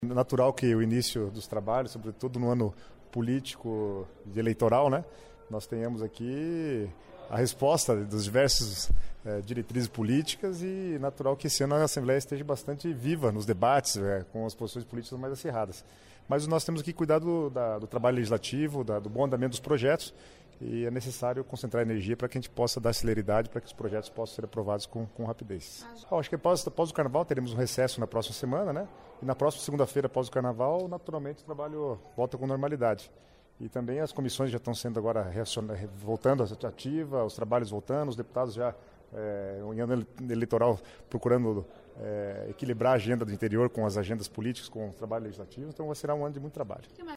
Ouça entrevista com Guto Silva (PSD)